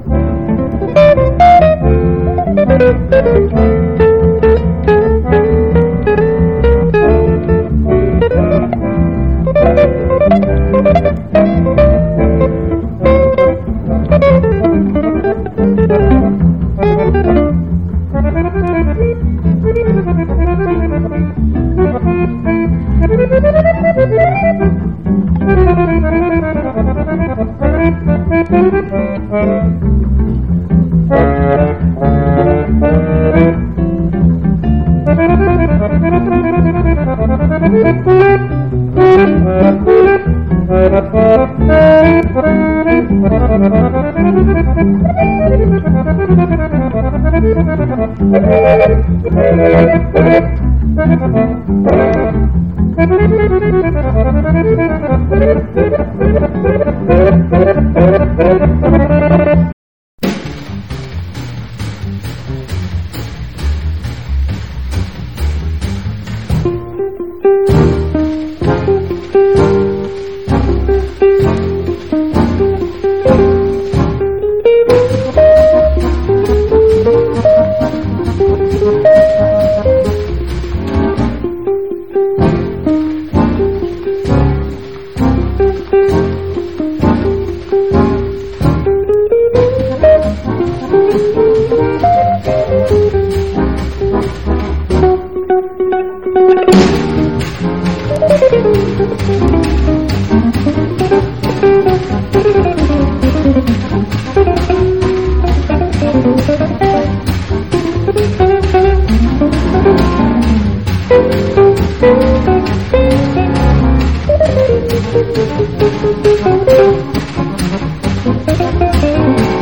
JAZZ / DANCEFLOOR / DRUM BREAK / RARE GROOVE / FUNK / LOUNGE
ラウンジ系のクラブ・ジャズ & レア・グルーヴ・クラシック盛りだくさんのグレイト・コンピ！